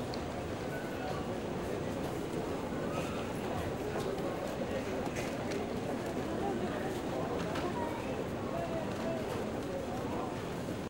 hallway.ogg